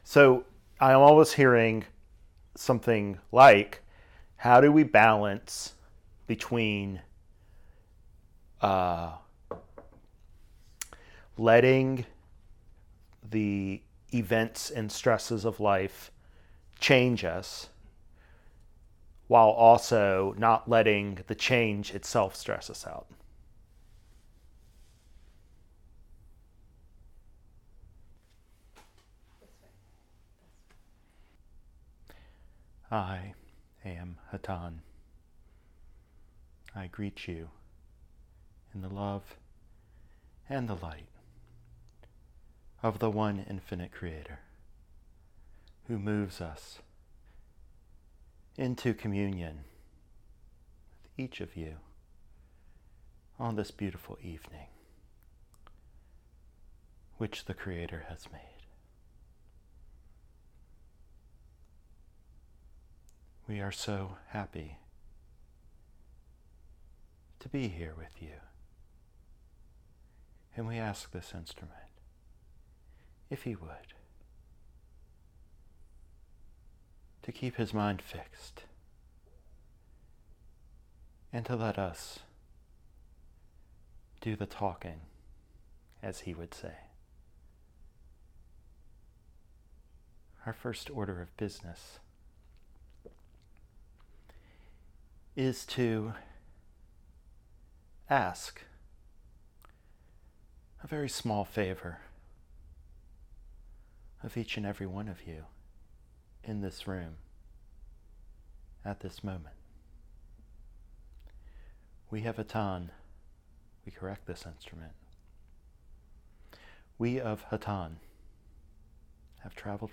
In this inaugural channeling session of the Richmond Meditation Circle, Hatonn counsels seekers to hone their faith by leaning into the catalyst we programmed for ourselves, painful though it may be.